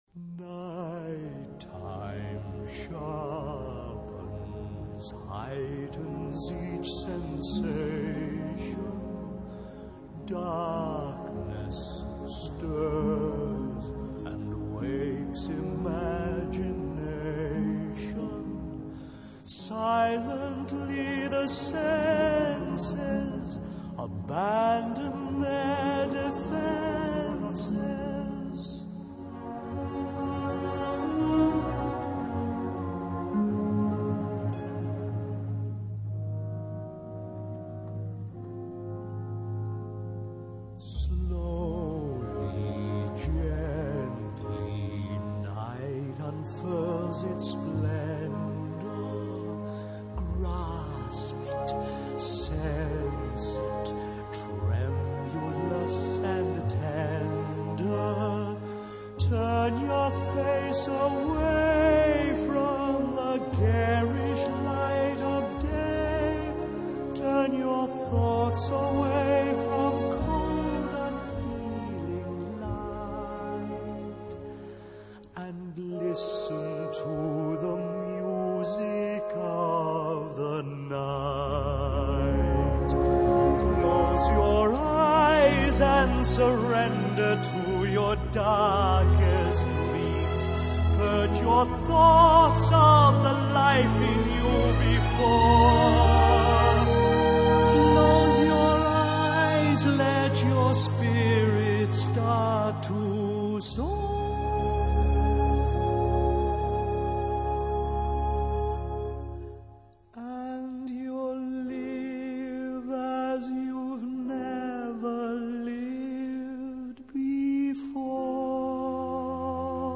這是 Phantom 把 Christine 帶回自己的地下王國時，自抒情懷所唱的歌。